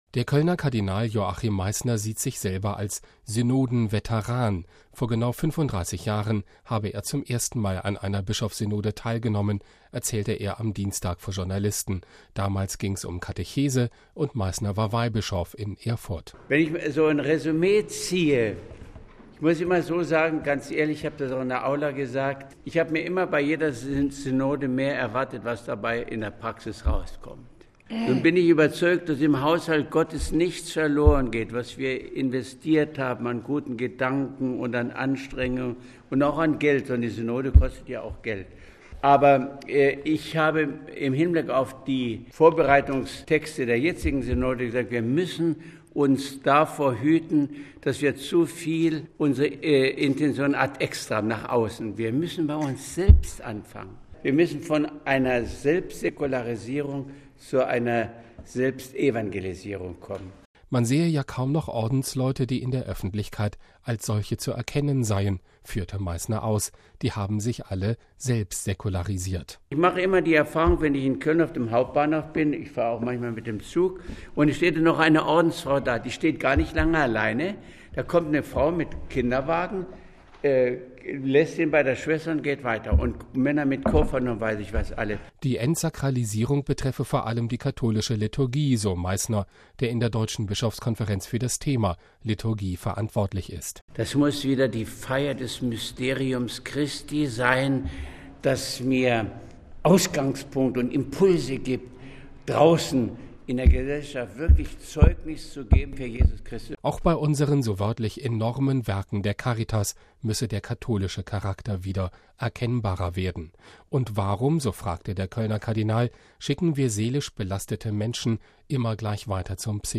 MP3 Der Kölner Kardinal Joachim Meisner sieht sich selbst als „Synodenveteran“: Vor genau 35 Jahren habe er zum ersten Mal eine Bischofssynode besucht, erzählte er am Dienstag vor Journalisten.